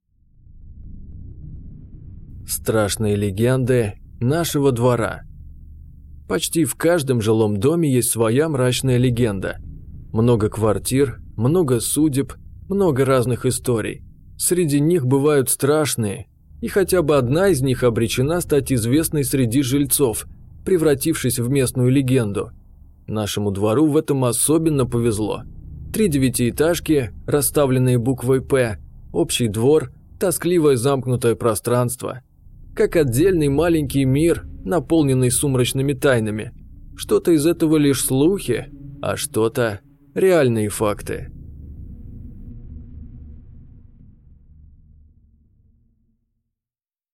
Аудиокнига Рассвет никогда не настанет | Библиотека аудиокниг